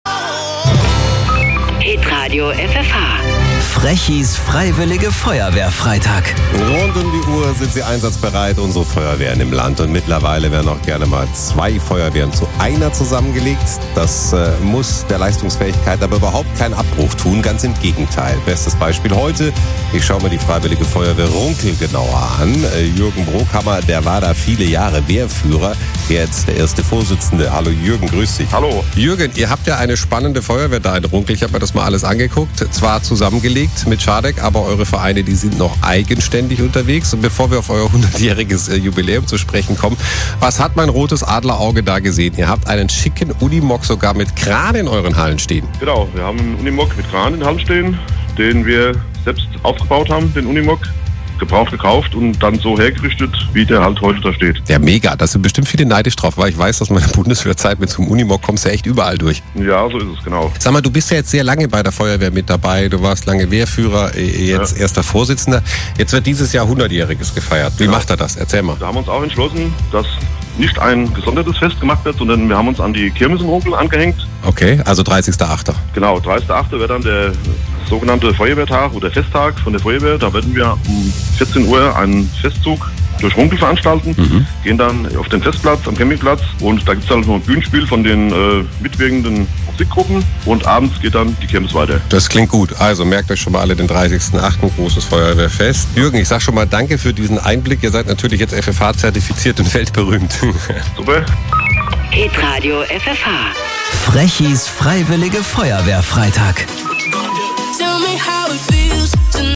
Die Freiwillige Runkel im Radio FFH: